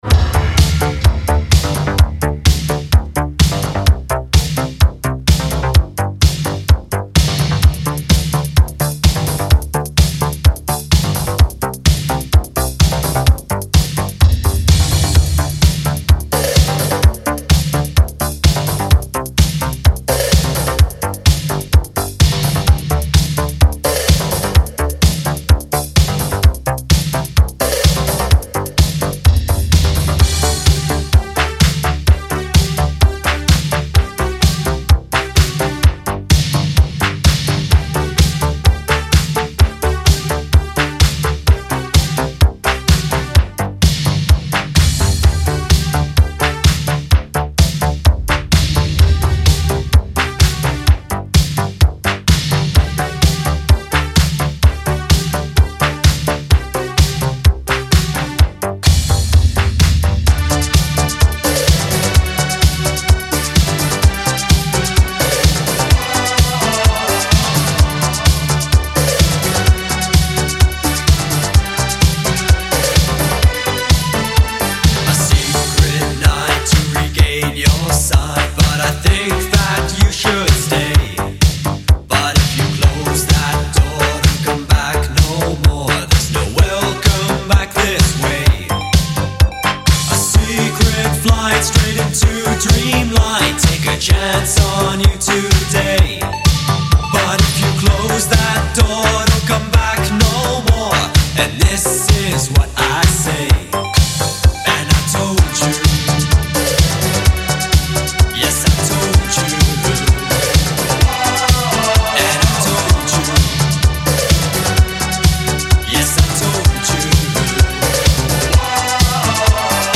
The result is an uptempo cracker with classic melodies.